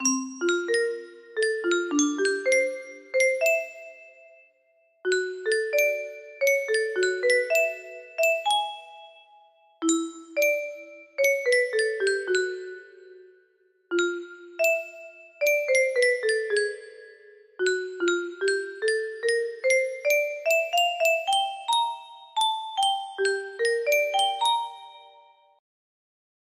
S T Original music box melody